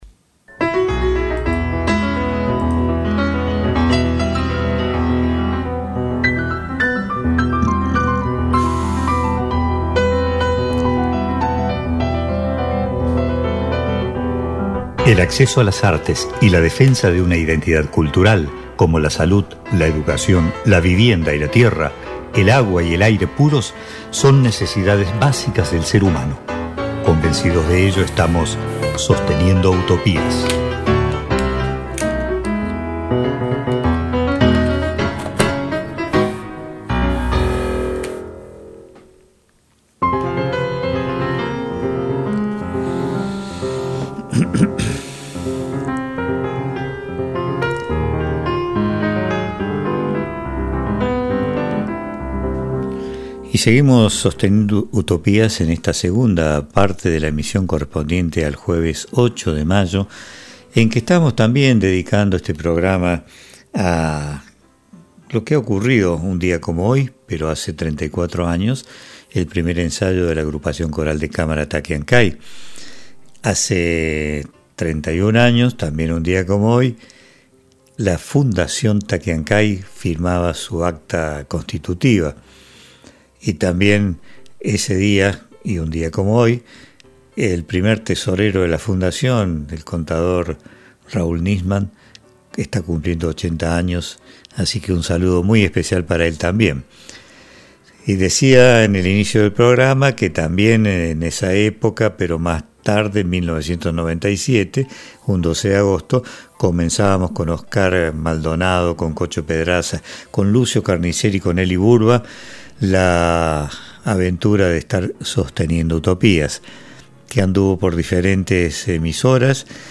Prontos a comenzar una etapa de descanso, esta será la emisión en vivo antes de pasar a compartir algunas ya programadas para ser emitidas en los próximos jueves 15, 22 y 29 de mayo y 5 y 12 de junio.